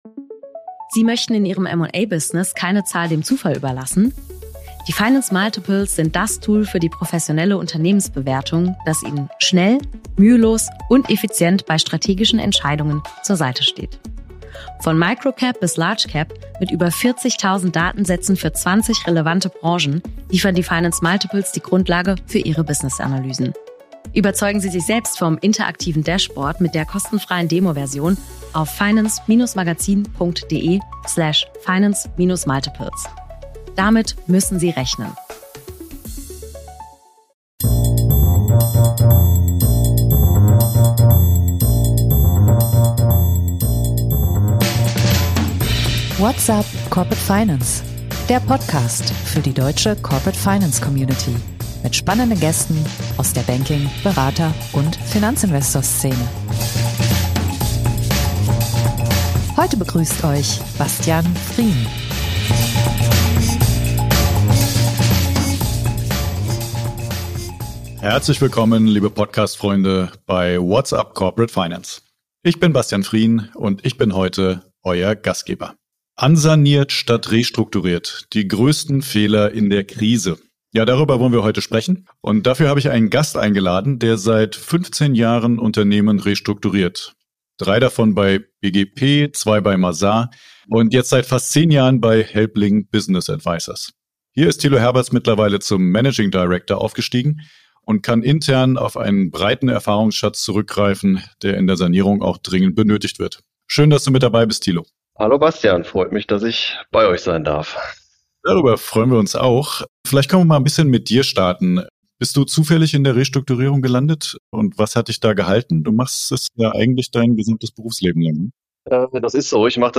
Wir sprechen in dieser Episode mit einem erfahren Restrukturierungsberater über gängige Fehler und über die richtigen Rettungsmaßnahmen für angeschlagene Unternehmen.